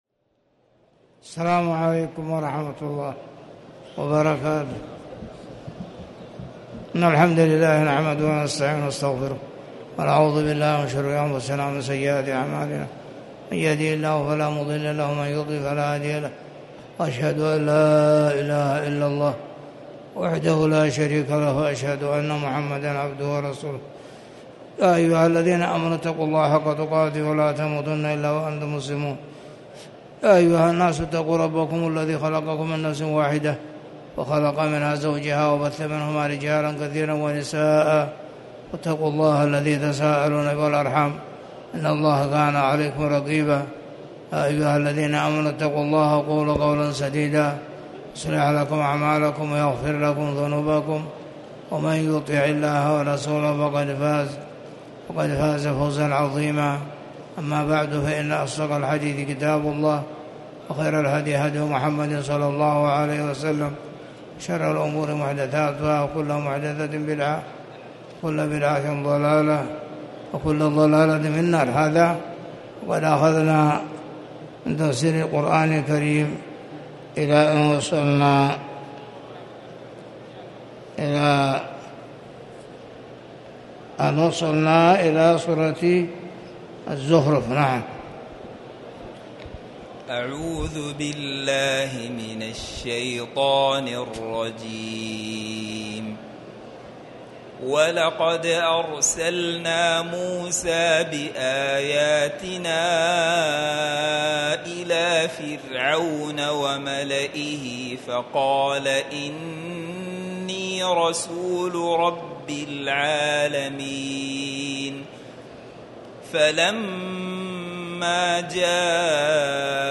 تاريخ النشر ٩ محرم ١٤٤٠ هـ المكان: المسجد الحرام الشيخ